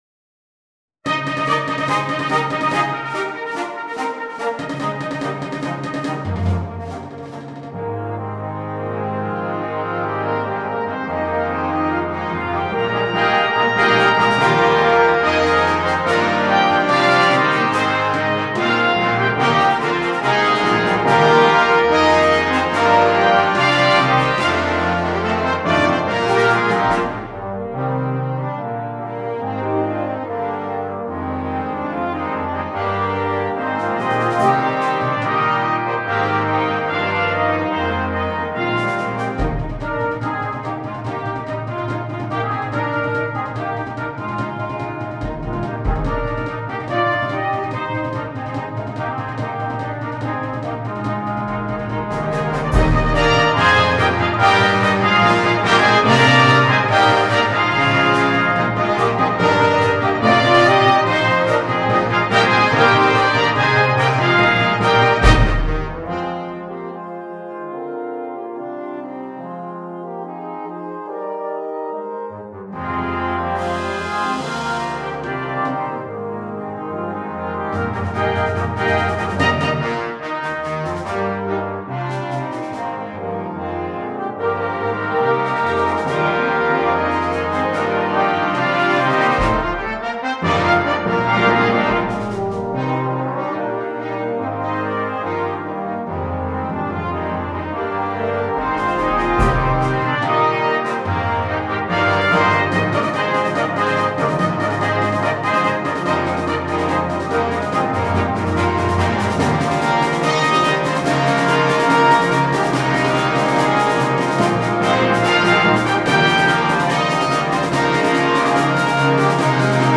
2. Brass Band
komplette Besetzung
ohne Soloinstrument
Unterhaltung